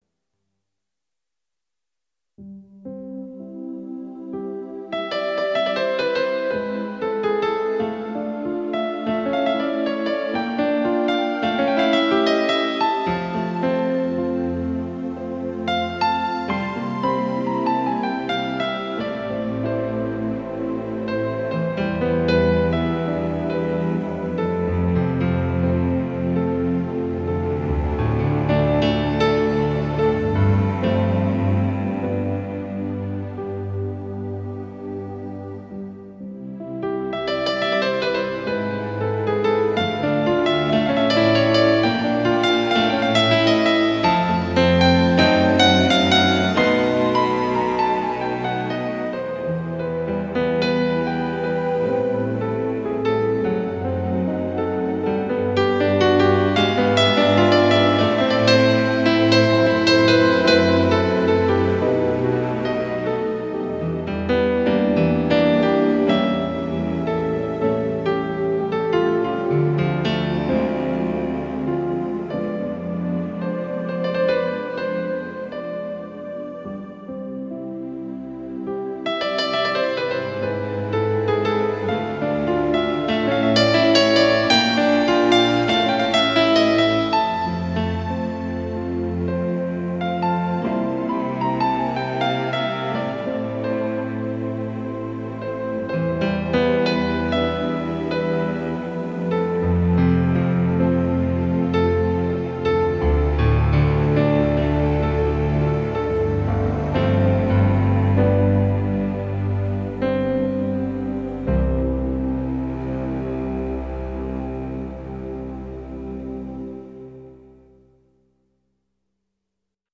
Ноктюрн_1 (открыта)